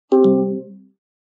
На этой странице собраны звуки Apple AirPods: сигналы подключения, уведомления о батарее, системные эффекты и другие аудиоэлементы.
Звук отключения беспроводных наушников Apple AirPods